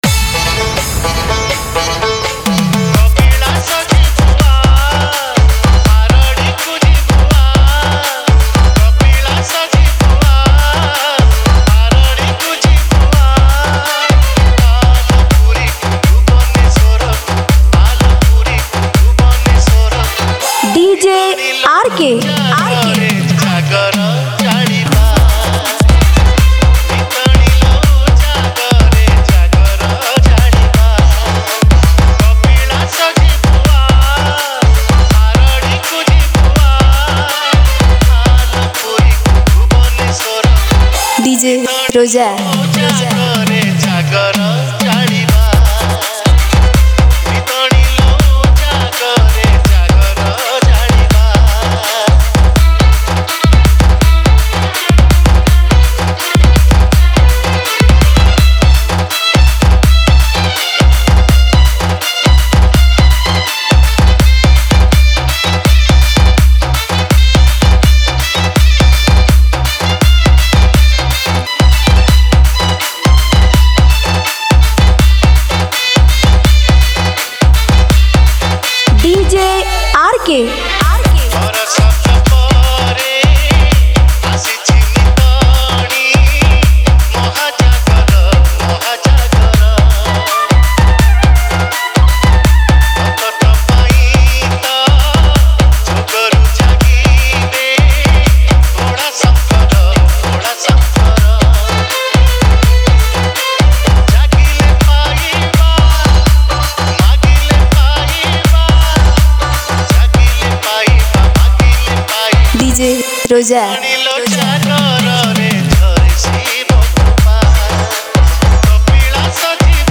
Shivratri Special DJ Remix Songs Songs Download